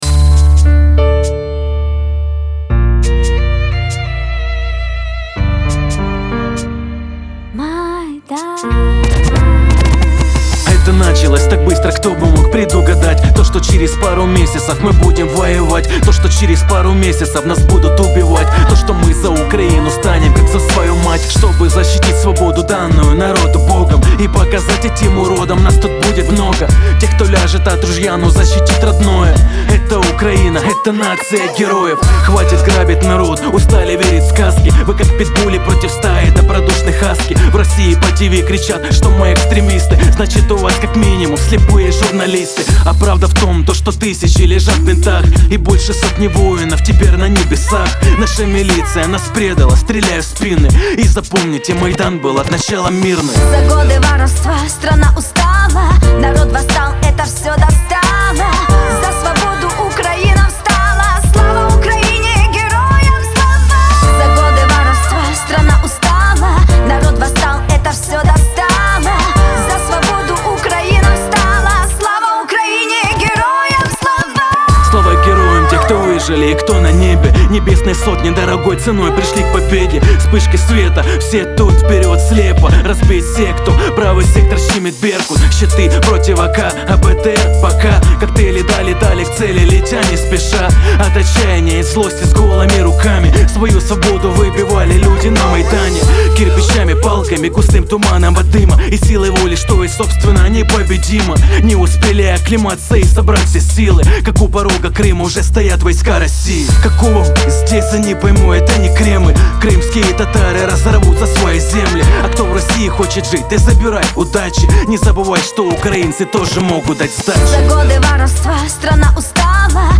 UKRAINA_Edina_I_Ne_Pobedima___Zachital_rep_pro_Ukrainu_vksync_.mp3